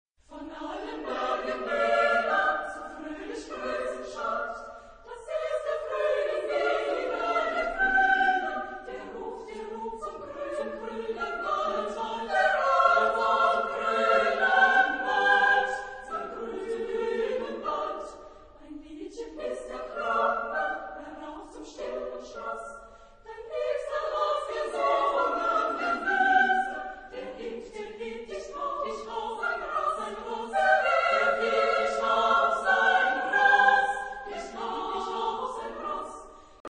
Genre-Style-Form: Choral song
Type of Choir: SSAA  (4 women voices )
Instruments: Piano (optional)
Tonality: E flat major
Discographic ref. : 7. Deutscher Chorwettbewerb 2006 Kiel